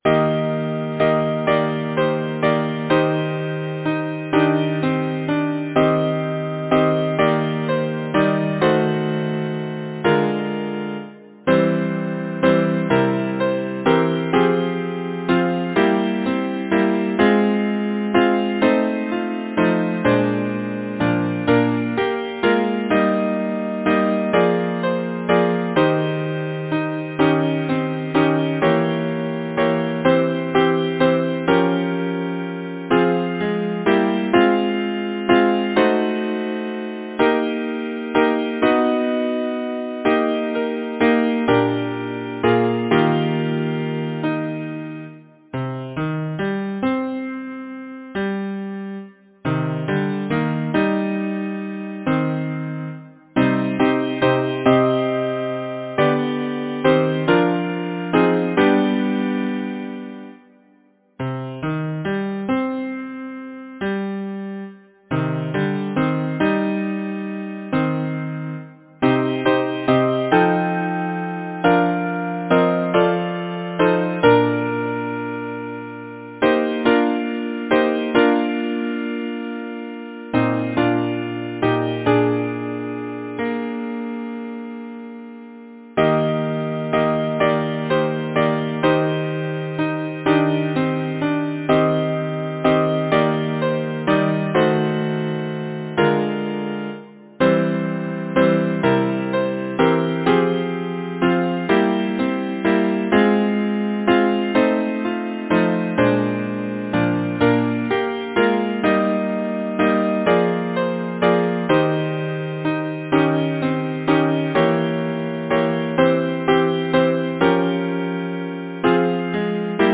Title: Breathe so softly, ye breezes Composer: Henry Alexander Donald Lyricist: Number of voices: 4vv Voicing: SATB Genre: Secular, Partsong
Language: English Instruments: A cappella